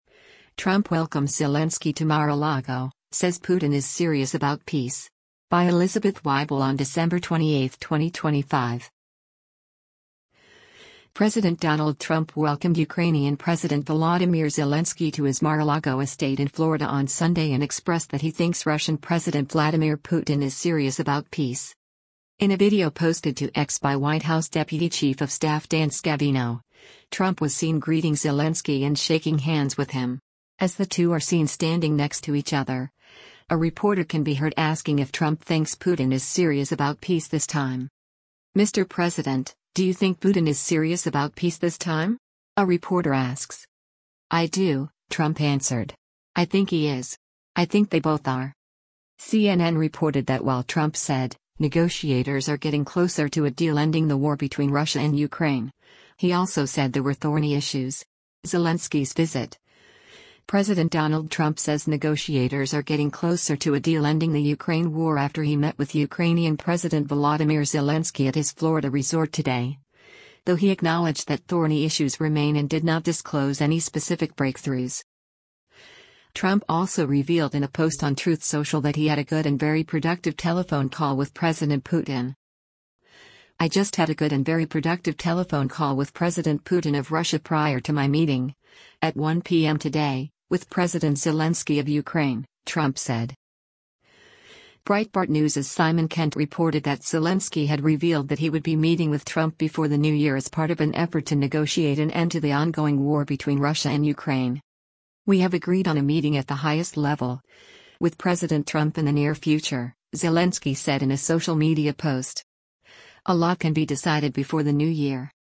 As the two are seen standing next to each other, a reporter can be heard asking if Trump thinks “Putin is serious about peace this time.”